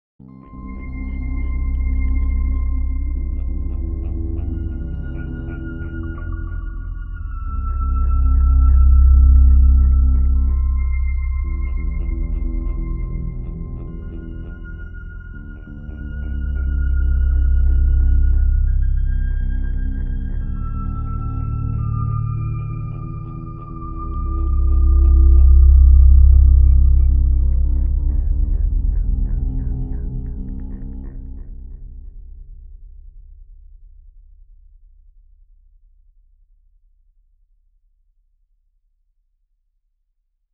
City of Mysteries --The Ambient Music Suite